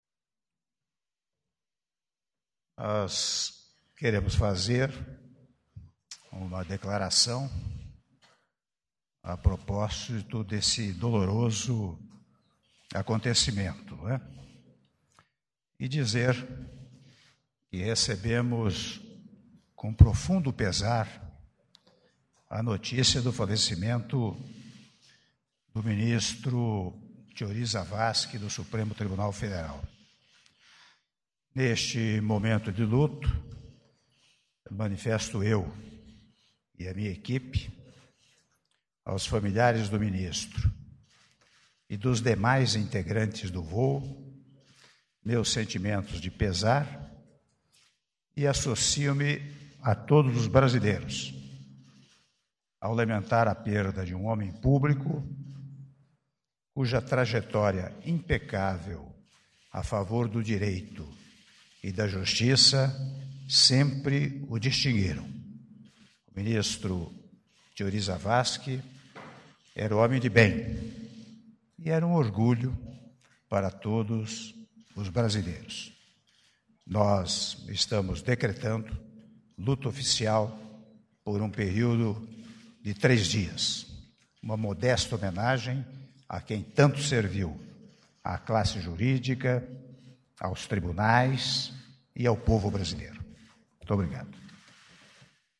Áudio da declaração à Imprensa do Presidente da República, Michel Temer, sobre o falecimento do Ministro Teori Zavascki - (01min35s) - Brasília/DF